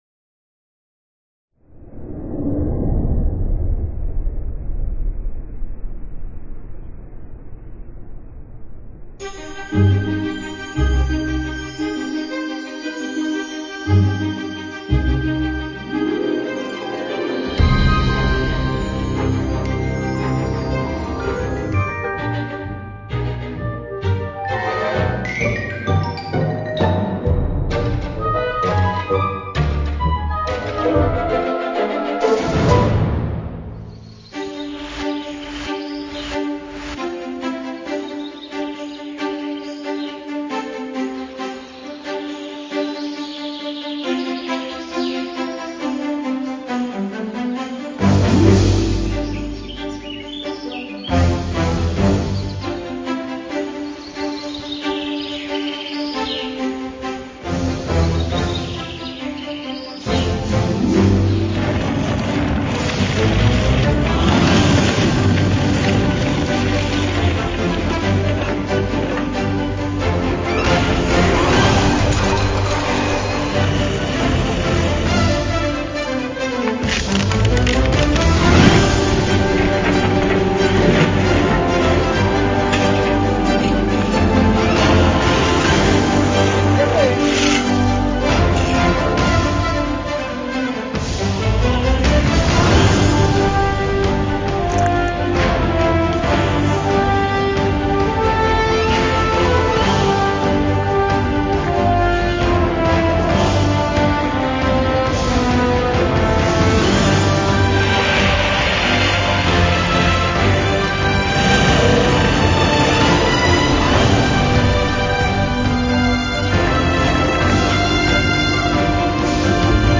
Zasedání zastupitelstev - Hlídač státu.